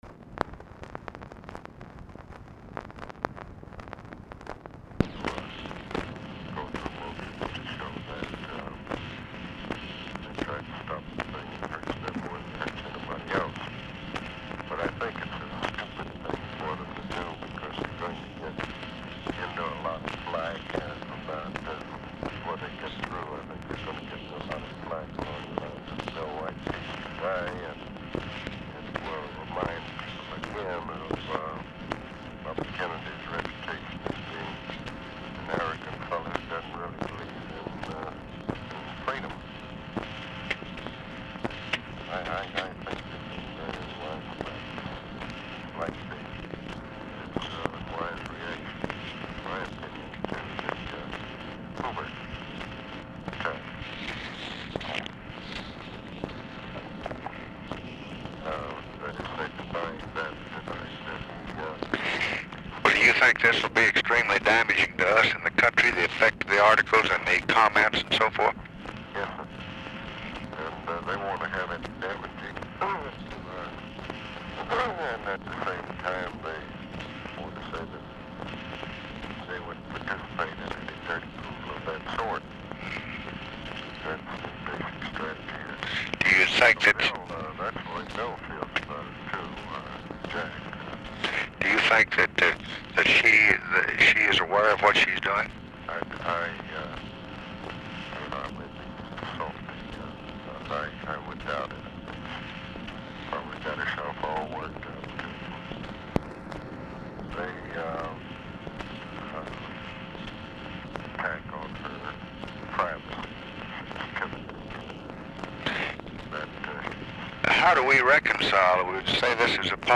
Telephone conversation
POOR SOUND QUALITY; FORTAS IS DIFFICULT TO HEAR
Format Dictation belt